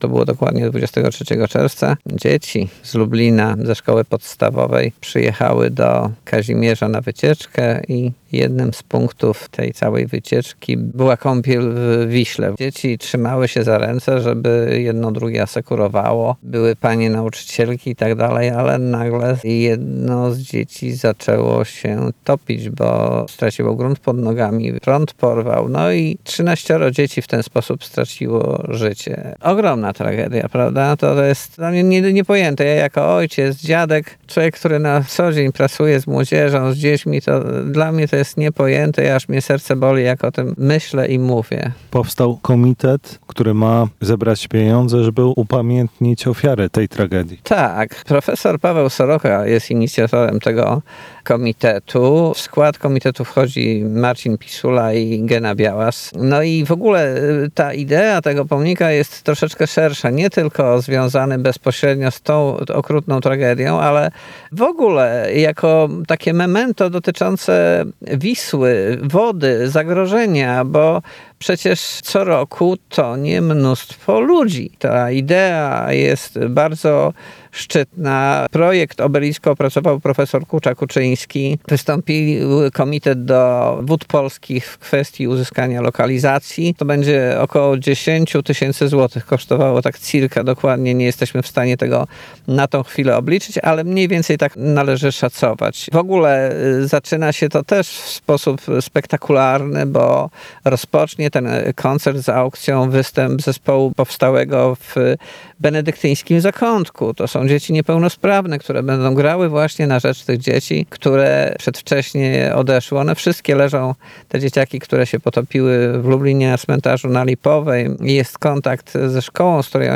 Radio Lublin > Audycje > Gorąca linia Radia Lublin > 30.09.2025 Gorąca linia Radia Lublin